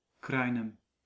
Kraainem (Dutch pronunciation: [ˈkraːinɛm]
Nl-Kraainem.ogg.mp3